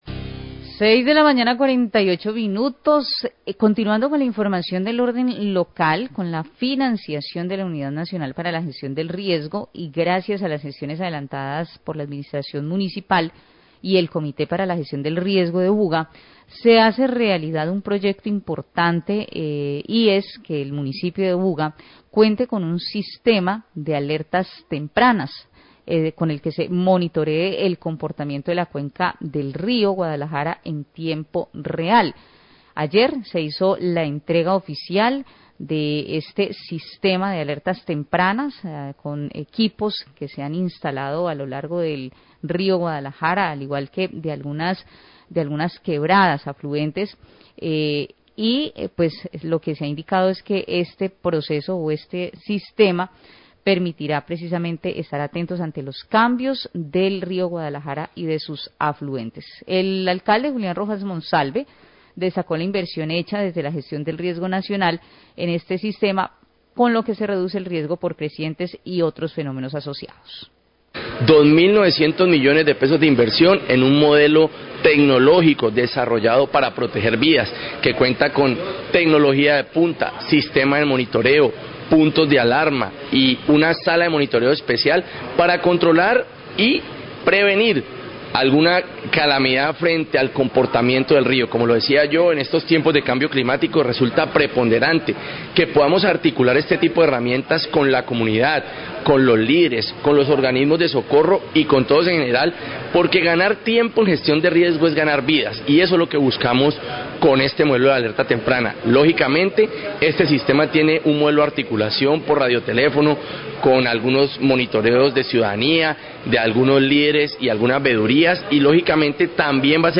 Alcalde Buga y funcionario UNGRD hablan del sistema de alertas tempranas para Río Guadalajara
Radio